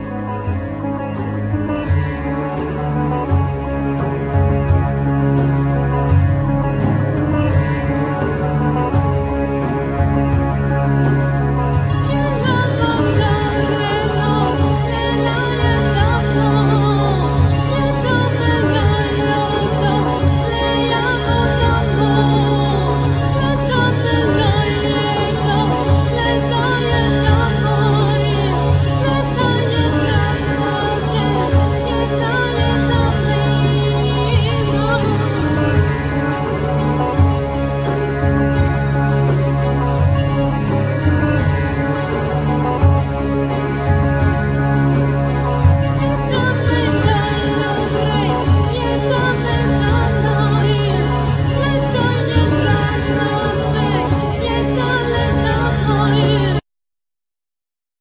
Voice
Santoor,Balalaika,Mandolin,Keyboards,Dulcimer,Shaker,